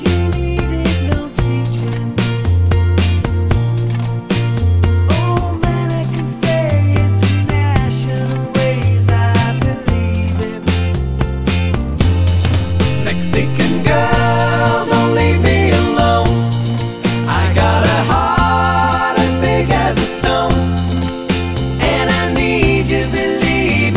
Backing Vocals Slightly Louder Pop (1970s) 3:59 Buy £1.50